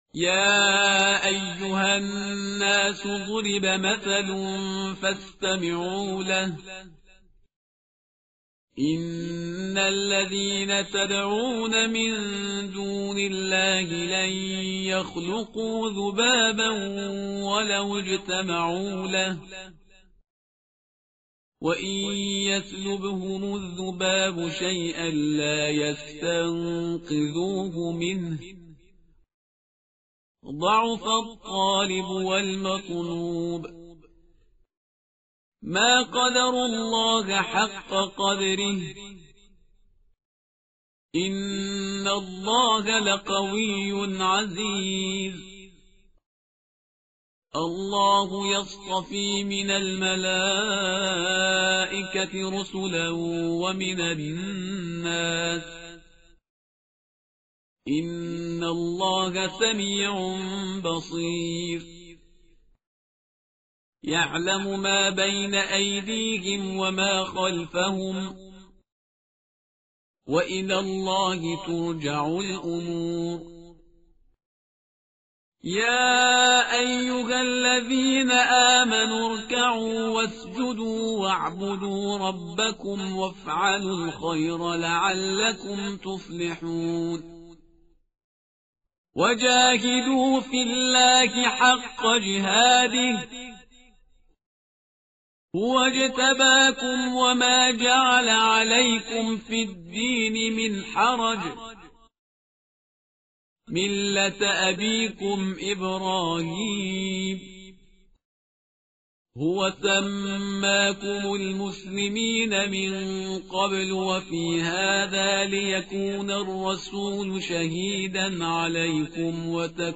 متن قرآن همراه باتلاوت قرآن و ترجمه
tartil_parhizgar_page_341.mp3